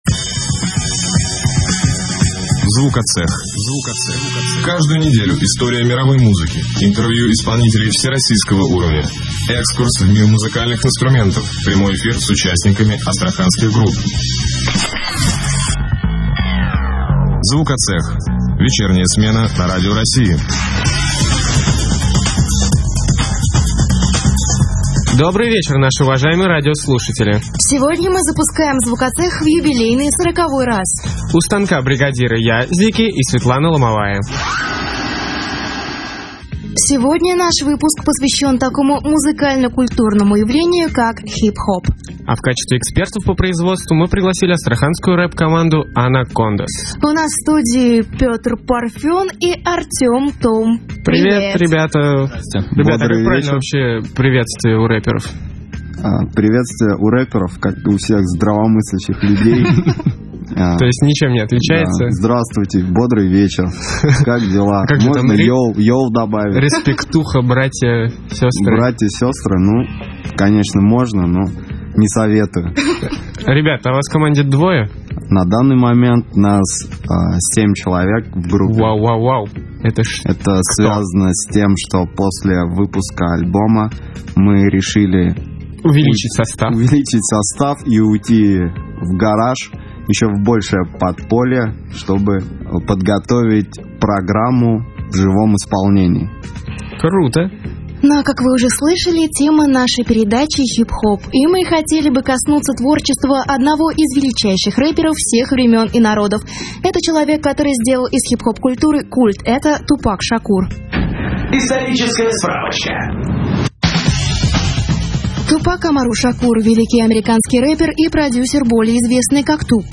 Anacondaz - Звукоцех - Выпуск 40 - Интервью с участниками группы скачать